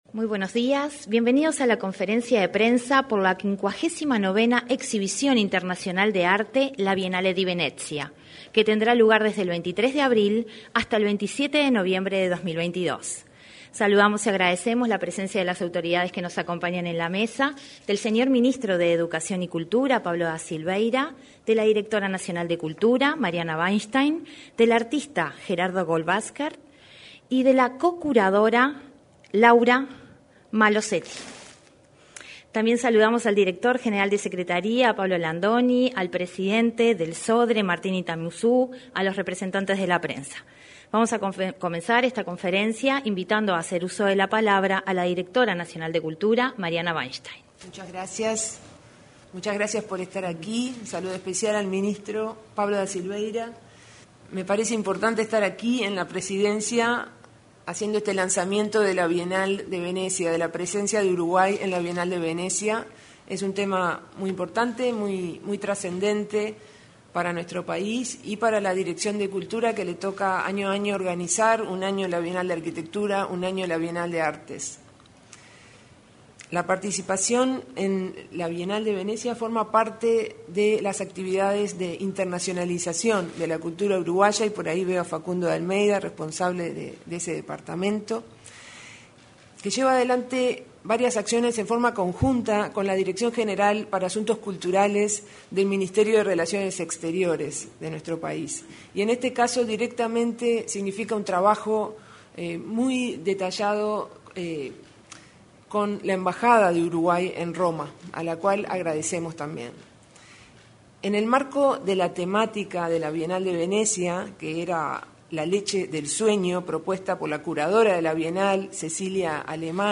Conferencia sobre la participación uruguaya en la Bienal de Venecia
Conferencia sobre la participación uruguaya en la Bienal de Venecia 18/03/2022 Compartir Facebook X Copiar enlace WhatsApp LinkedIn Este viernes 18, en el salón de actos de Torre Ejecutiva, el Ministerio de Educación y Cultura expuso sobre la participación uruguaya en la próxima Bienal de Venecia.